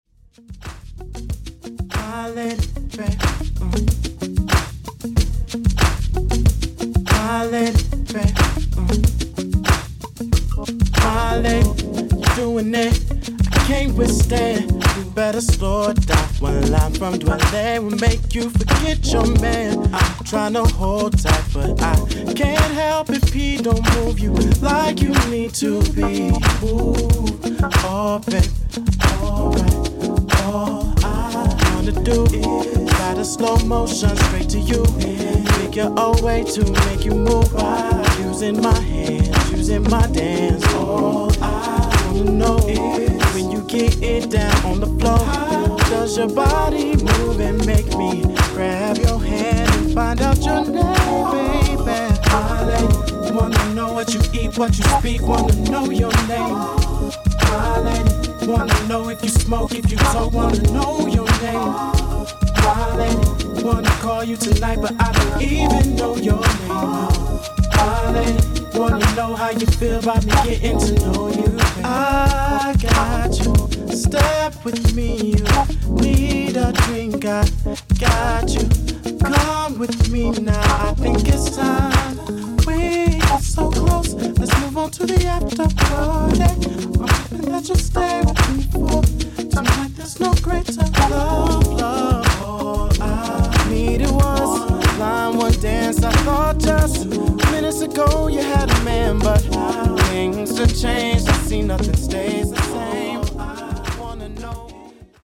Hit-n-run blends and vinyl only amends.